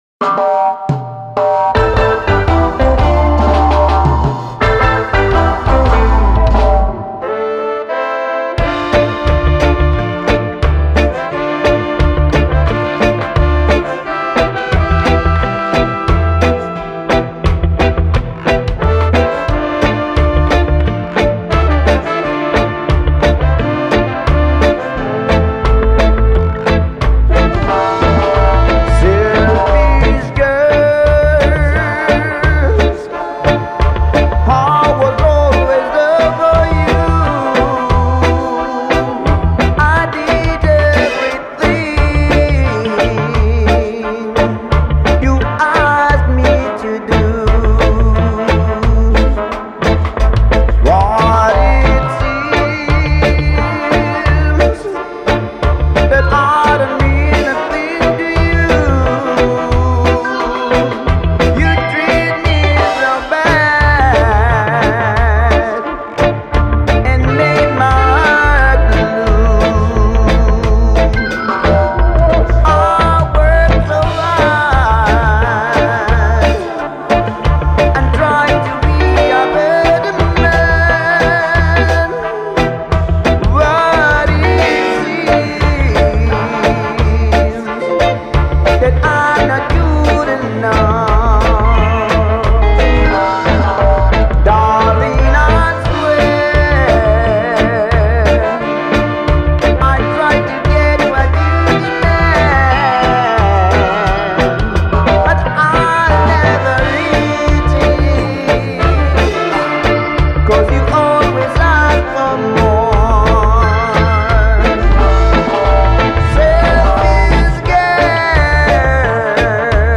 Description : Vocal + Instrumental